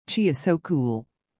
【ノーマル・スピード】